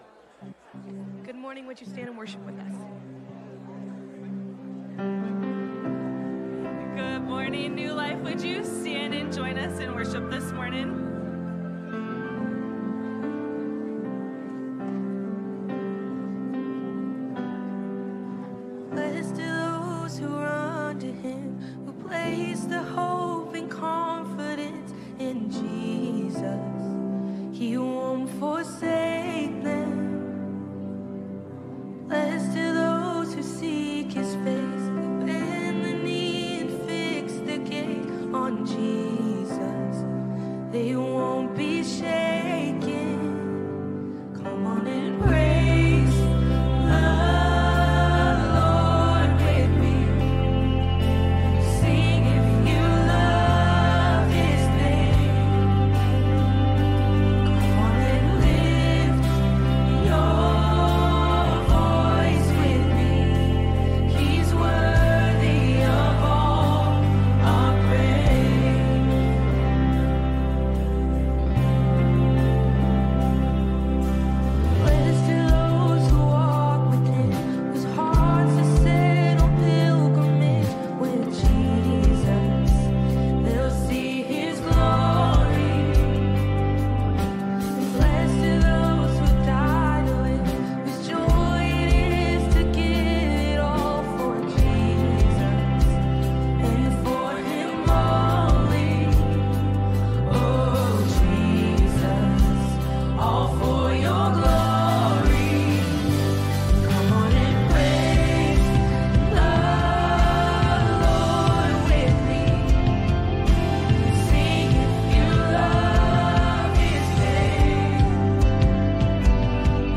Sermons - N E W L I F E